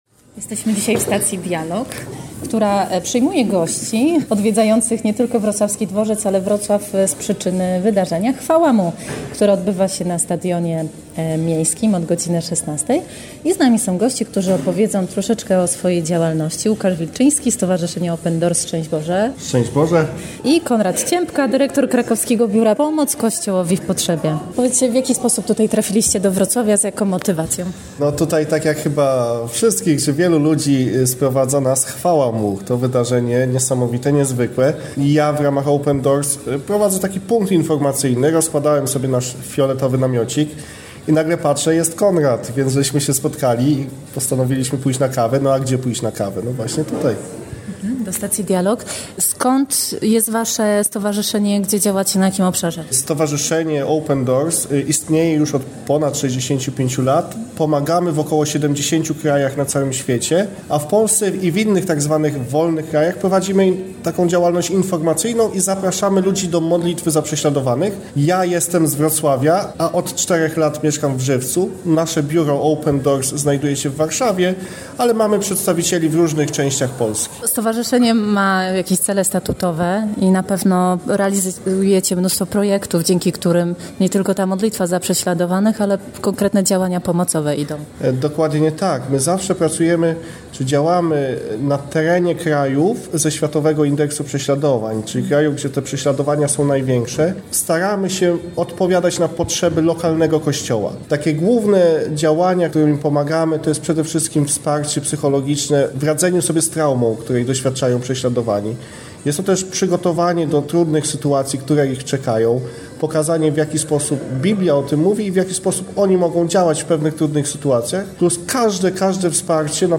O tym w rozmowie
Spotkaliśmy się w Stacji Dialog we Wrocławiu tuż przed wydarzeniem „Chwała Mu”.